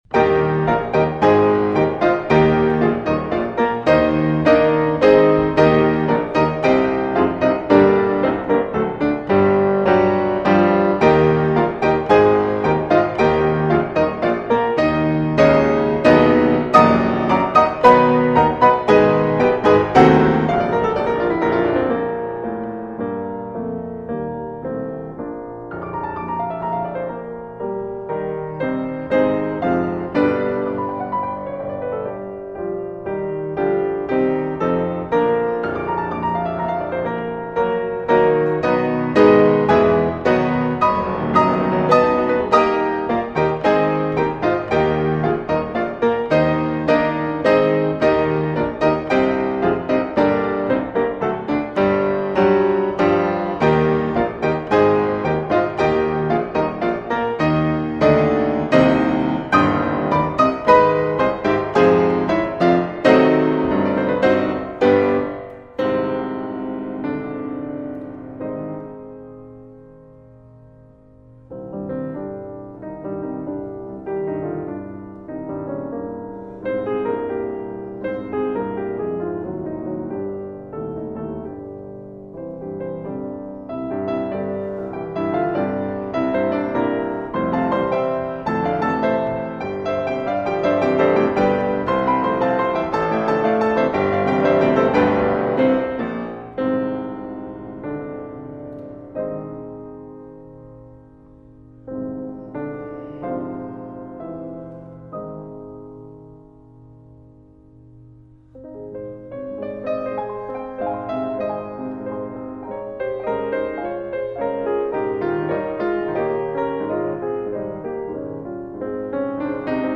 Klavier stücke. Piano